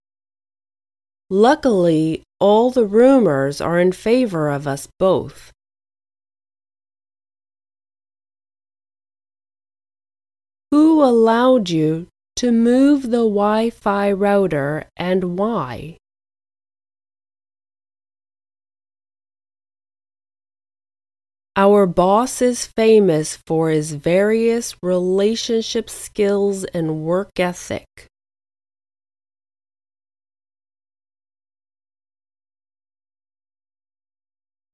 05_Lesson-05-Consonants-2.mp3